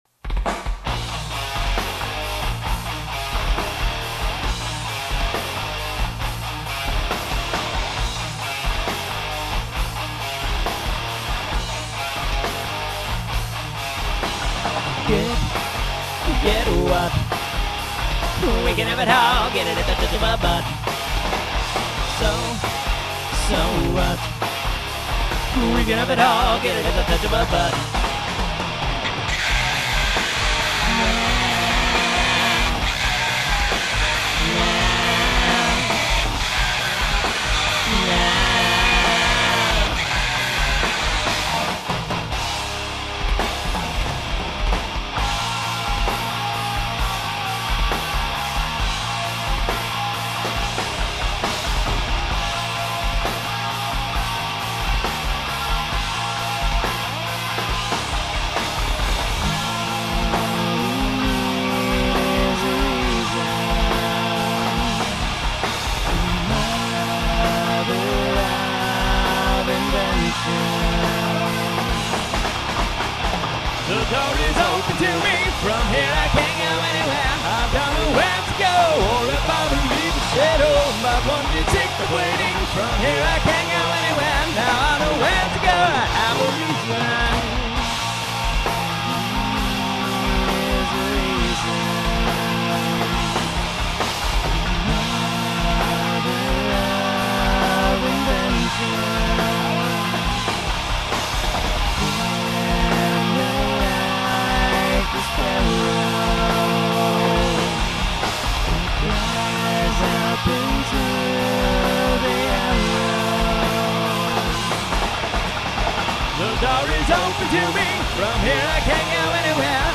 Take Two - No Bass, rough vox, unfinished
Unfortunately we had to give our pro studio mic back to it's owner.